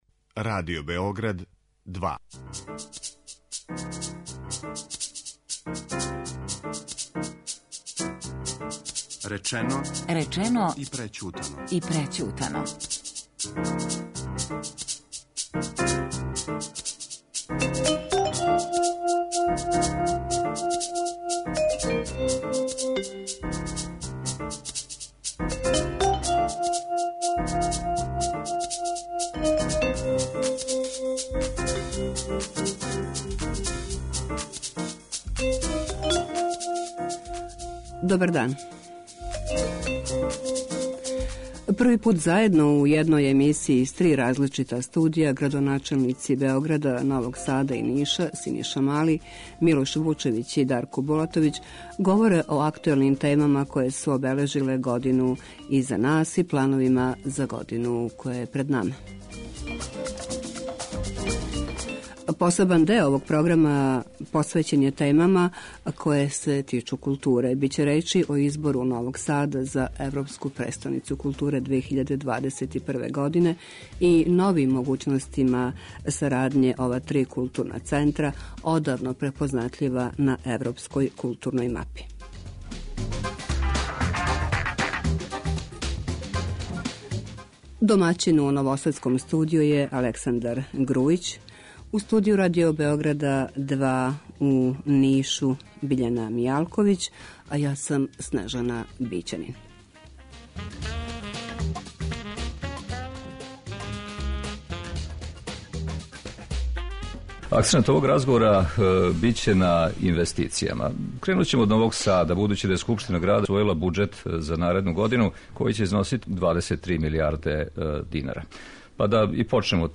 Први пут заједно, у једној емисији из три различита студија, градоначелници Београда, Новог Сада и Ниша - Синиша Мали, Милош Вучевић и Дарко Булатовић, говоре о актуелним темама које су обележиле годину која је иза нас и плановима за годину која долази.